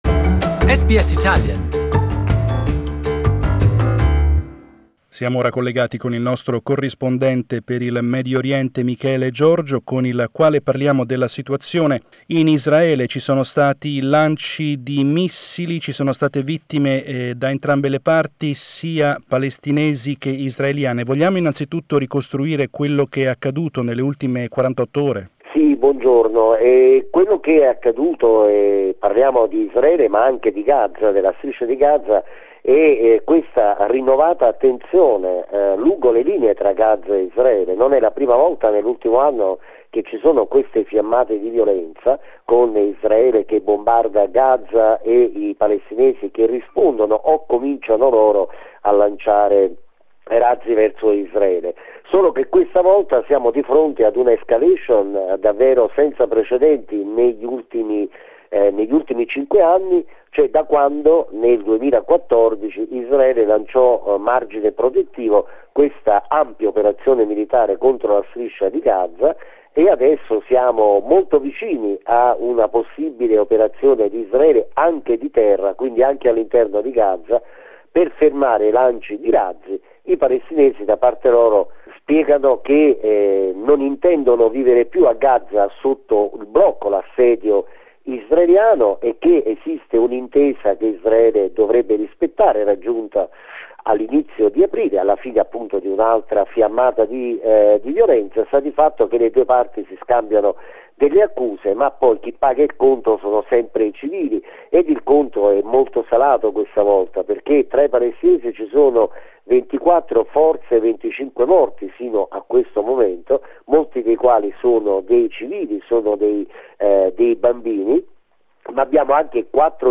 We analysed the situation with our correspondent from the Middle East